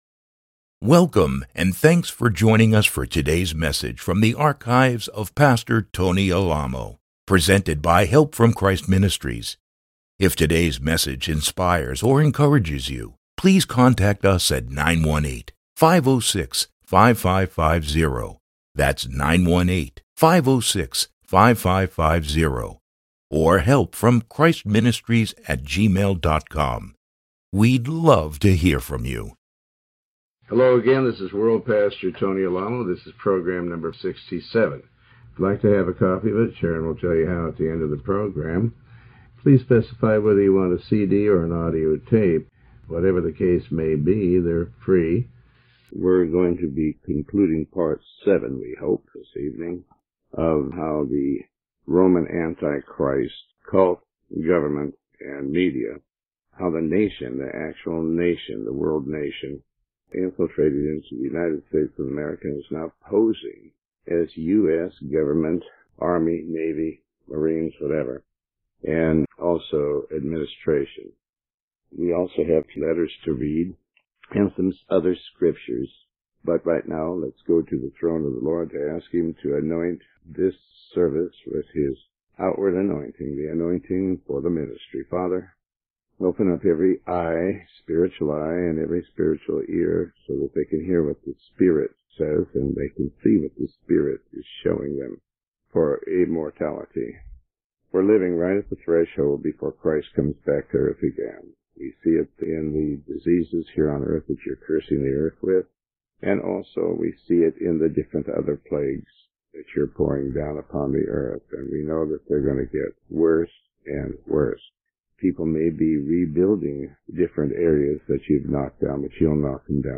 Sermon 67B